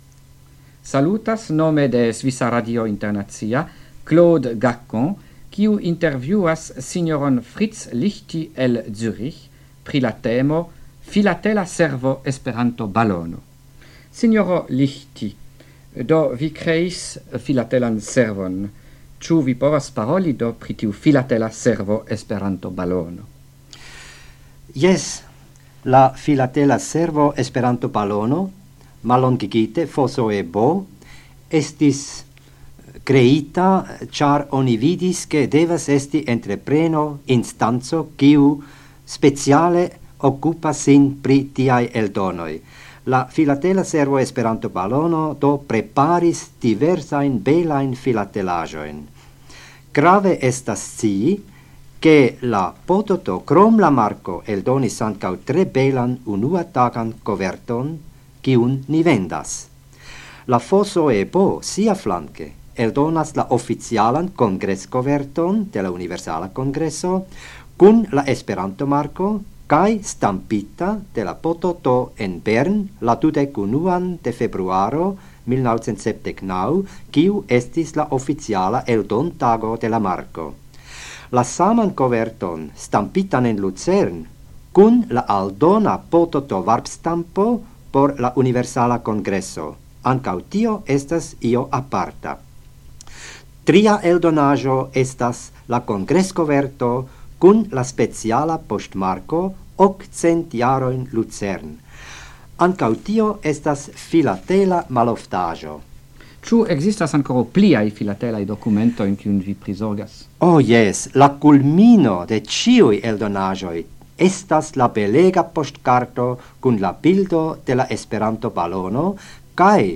Radioprelegoj en la jaro 1979
Intervjuo